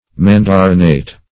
Search Result for " mandarinate" : The Collaborative International Dictionary of English v.0.48: Mandarinate \Man`da*rin"ate\, n. The collective body of officials or persons of rank in China.